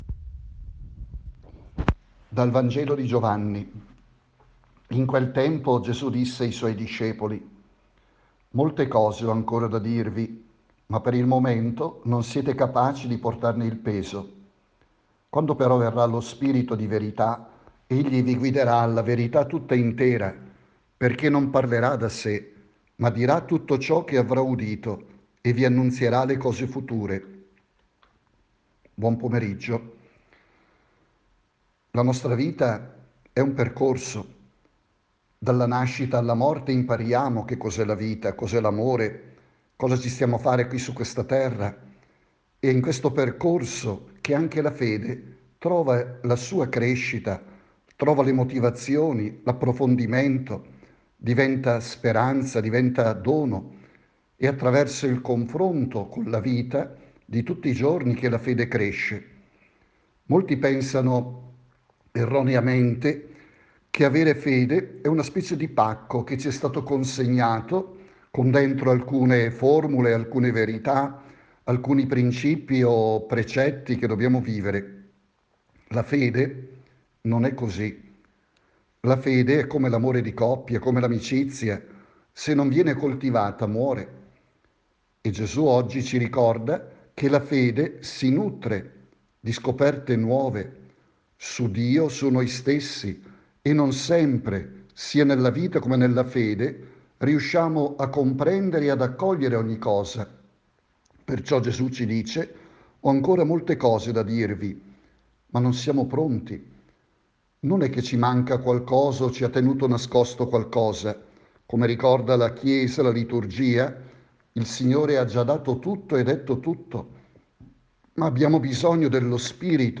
Messaggio